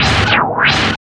99 NOISE  -R.wav